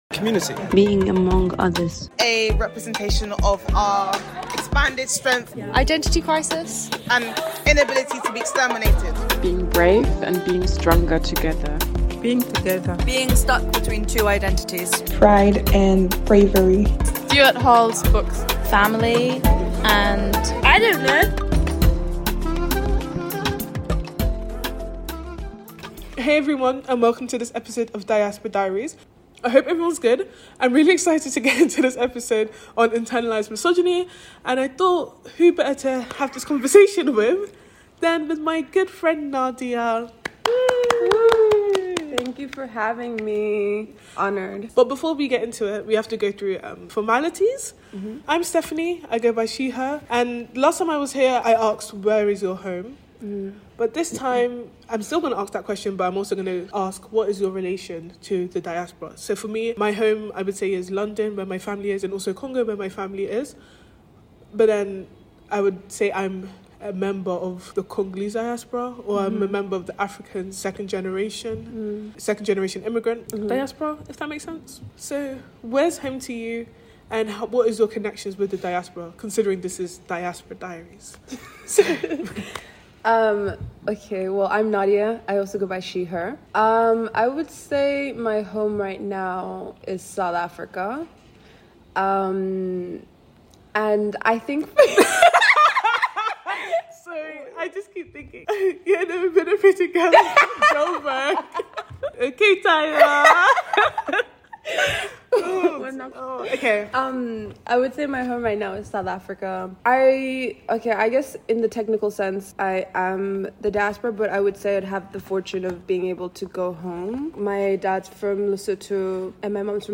They also touch on how internalized misogyny intersects with race, diaspora experiences, and societal expectations. The conversation aims to unpack and understand the complex ways women internalize and perpetuate misogynistic attitudes.